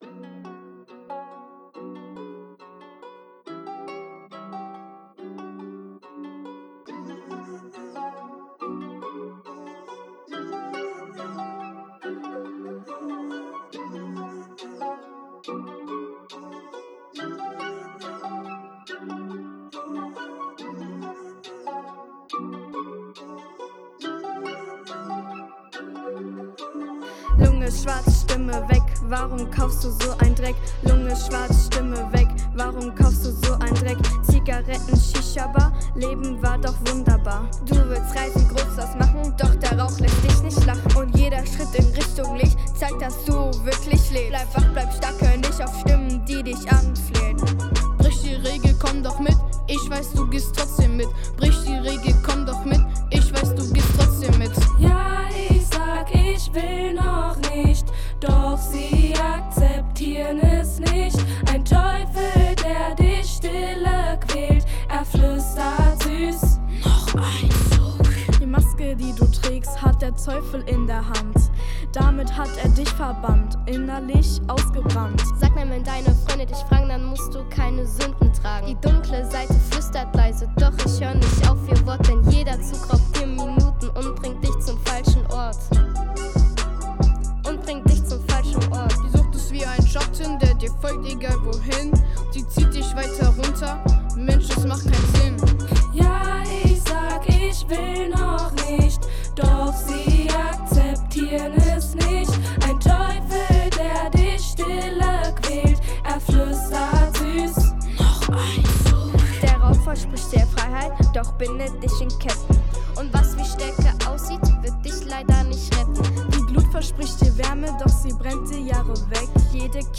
Leben ohne Qualm: Hip – Hop Workshop: Cooler Song ist fertig!
Der Rap Song, den die Schüler*innen in den Wochen zuvor selbständig erarbeitet hatten, wurde nun endlich professionelle aufgenommen und abgemischt. Im Fokus stand die Auseinandersetzung mit dem Thema Rauchen.